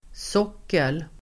Uttal: [²s'åk:el]